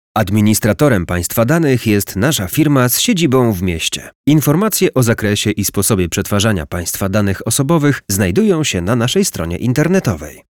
Przykład lektorskiej zapowiedzi o przetwarzaniu danych: